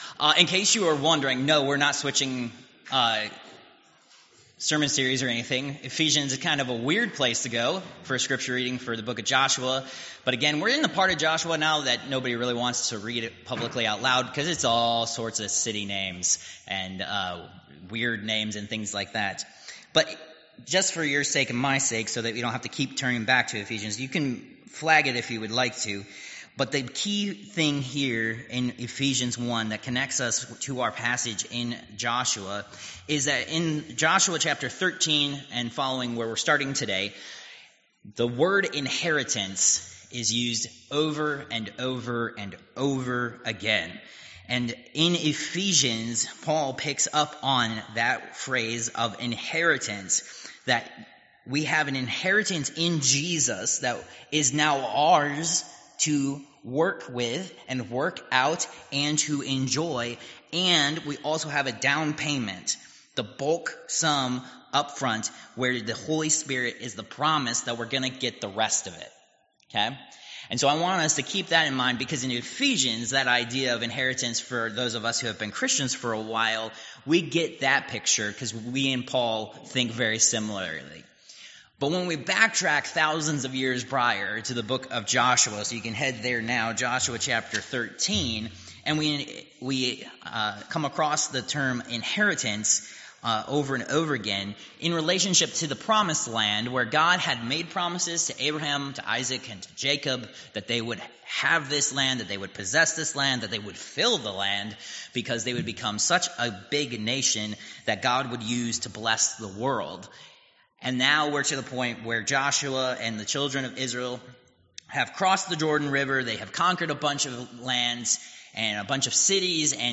Josh. 13:1-14:5 Service Type: Worship Service Download Files Notes « Protected